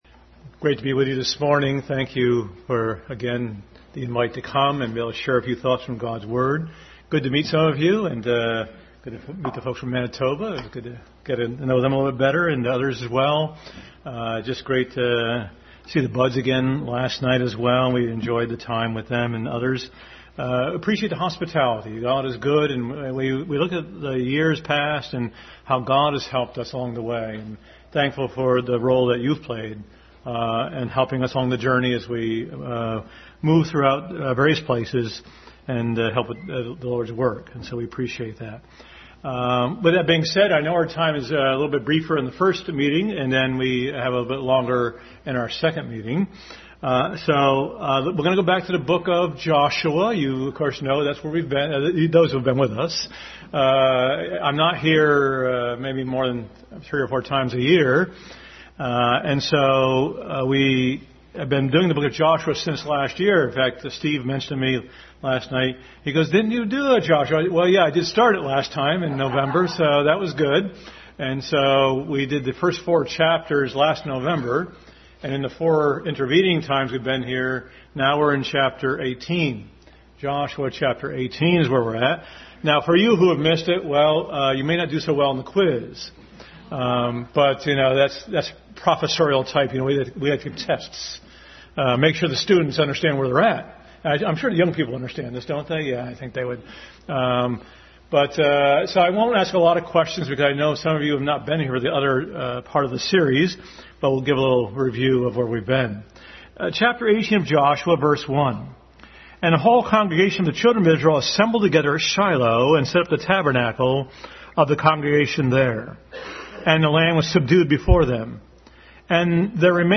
Adult Sunday School.